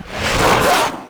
VEC3 Reverse FX
VEC3 FX Reverse 31.wav